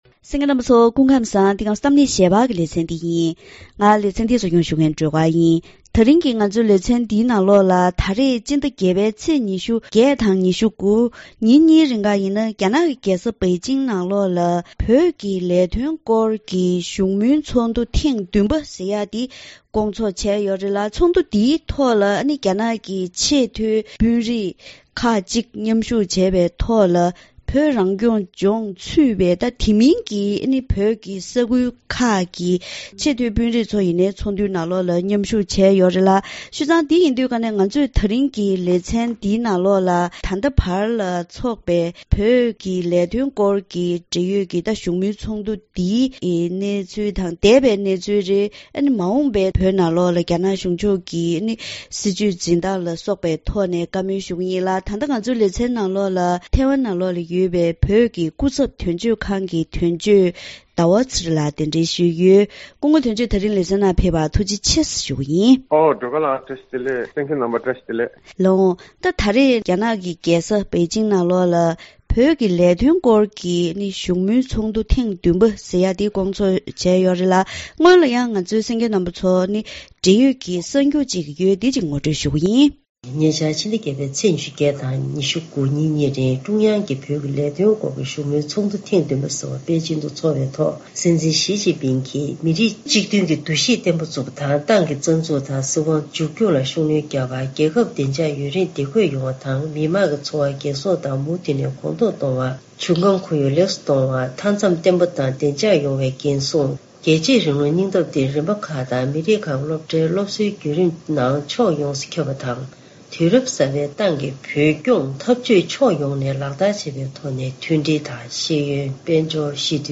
ད་རིང་གི་གཏམ་གླེང་ཞལ་པར་ལེ་ཚན་ནང་ཉེ་ཆར་རྒྱ་ནག་གི་རྒྱལ་ས་པེ་ཅིང་དུ་རྒྱ་ནག་དམར་ཤོག་གཞུང་གིས་བོད་ཀྱི་ལས་དོན་སྐོར་གྱི་ཚོགས་འདུ་ཐེངས་བདུན་པ་འདི་སྐོར་ཚོགས་བྱས་ཡོད་པས། ཐེངས་འདིའི་ཚོགས་འདུའི་ཁྲོད་ནས་ཐོན་པའི་དམ་བསྒྲགས་ཀྱི་སྲིད་ཇུས་དང་འདས་པའི་ཚོགས་འདུ་ཁག་གི་གནས་ཚུལ། དེ་བཞིན་མ་འོངས་པར་བོད་ས་ཡོངས་ནང་རྒྱ་ནག་དམར་ཤོག་གཞུང་གིས་སྲིད་ཇུས་དང་གནས་སྟངས་ཀྱི་འཕེལ་ཕྱོགས་སོགས་ཕྱོགས་མང་པོའི་ཐོག་འབྲེལ་ཡོད་གནས་དོན་དབྱེ་ཞིབ་པ་དང་ལྷན་དུ་བགྲོ་གླེང་ཞུས་པ་ཞིག་གསན་རོགས་གནང་།